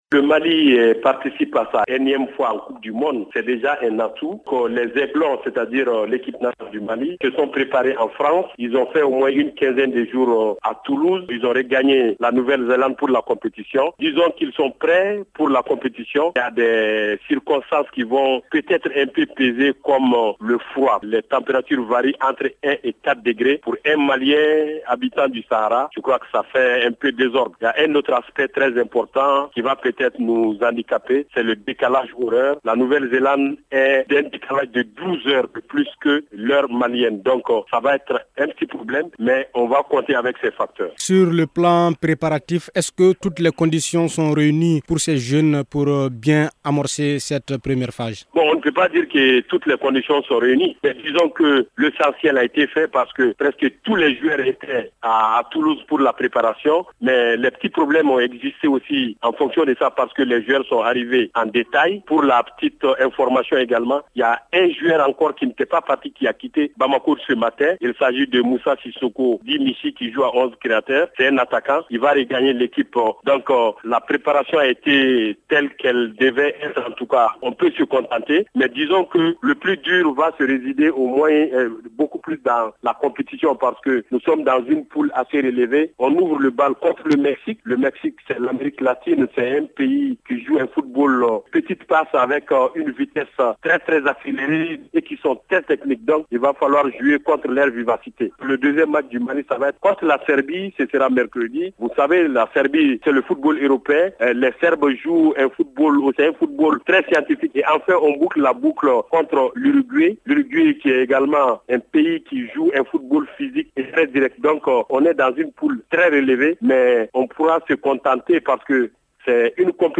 Il est joint au téléphone par